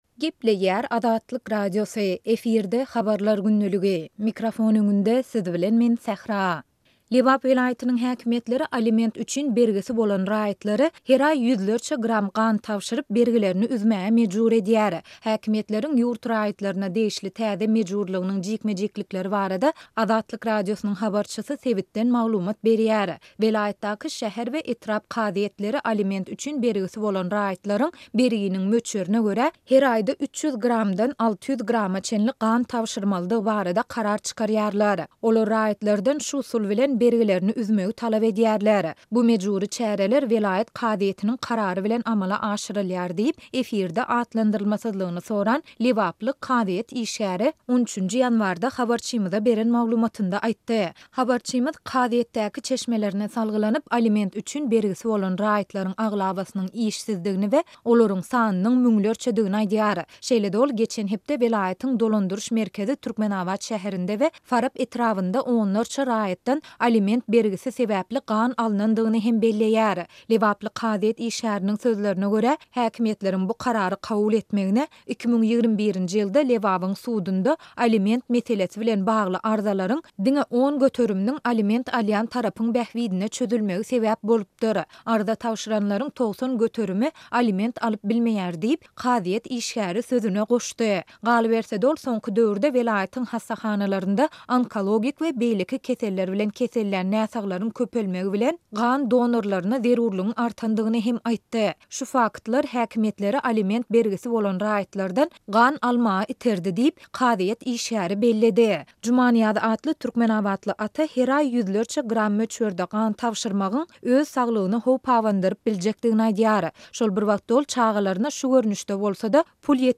Lebap welaýatynyň häkimiýetleri aliment üçin bergisi bolan raýatlary her aý ýüzlerçe gram gan tabşyryp, bergilerini üzmäge mejbur edýär. Häkimiýetleriň ýurt raýatlaryna degişli täze mejburlygynyň jikme-jikleri barada Azatlyk Radiosynyň habarçysy sebitden maglumat berýär.